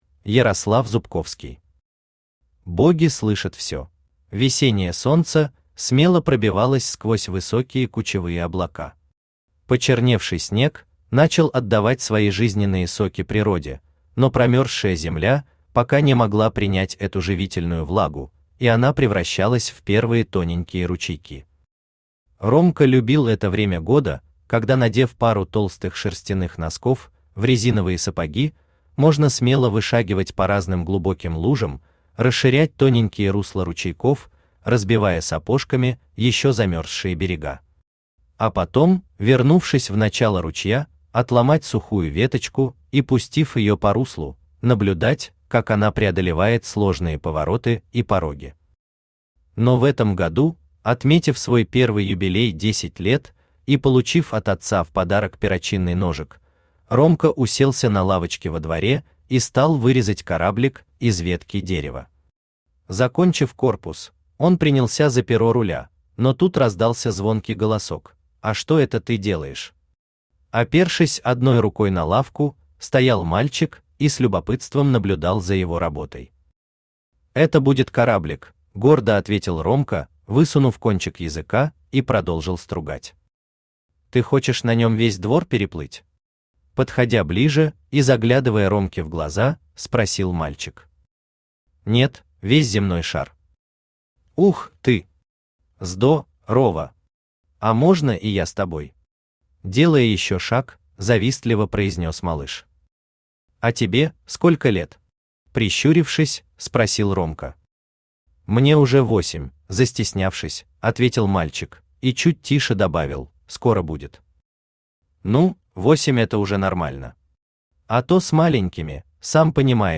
Аудиокнига Боги слышат всё | Библиотека аудиокниг
Aудиокнига Боги слышат всё Автор Ярослав Николаевич Зубковский Читает аудиокнигу Авточтец ЛитРес.